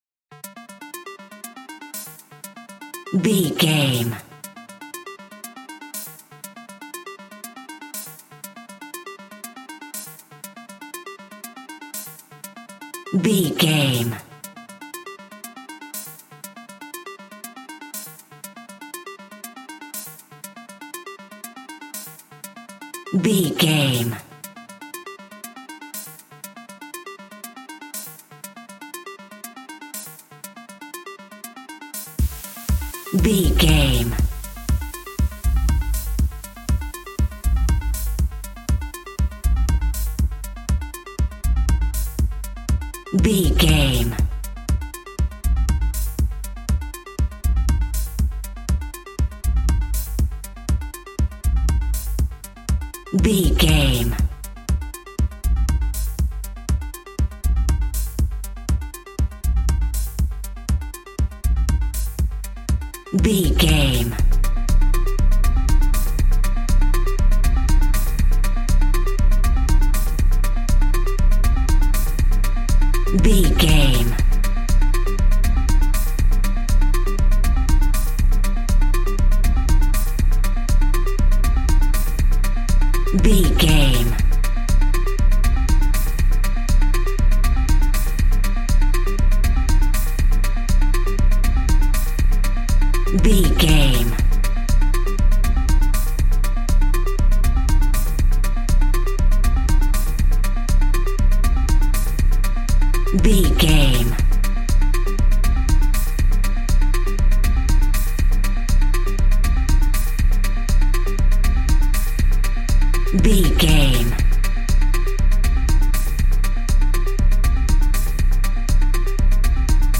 Epic / Action
Fast paced
Aeolian/Minor
dark
futuristic
groovy
aggressive
synthesiser
drum machine
vocal
house
electro dance
synth leads
synth bass
upbeat